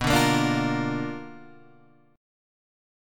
BmM7b5 chord {7 5 8 7 6 6} chord